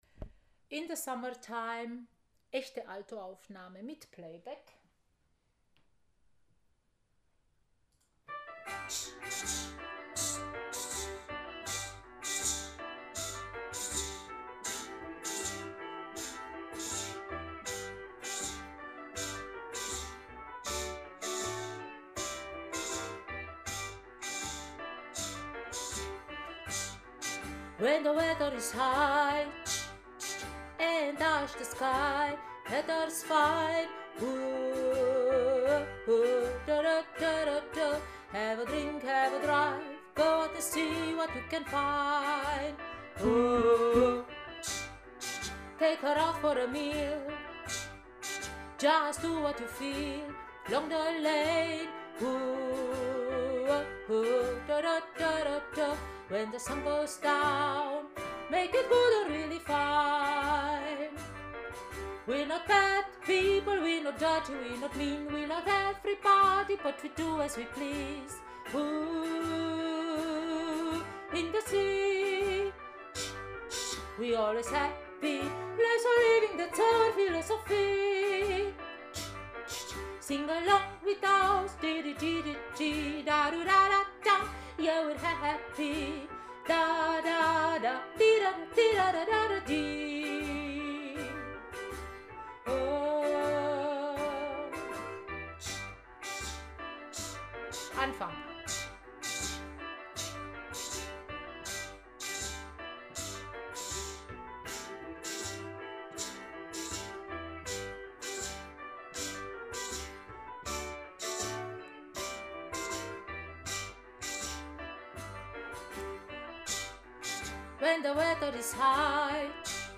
In the Summertime Alto Playback
in-the-Summertime-Alto-Playback.mp3